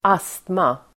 Ladda ner uttalet
astma substantiv, asthma Uttal: [²'as:tma] Böjningar: astman Definition: allergisk sjukdom som yttrar sig i andningsbesvär (an allergic disorder giving rise to difficulties in breathing) Sammansättningar: astma|anfall (attack of asthma)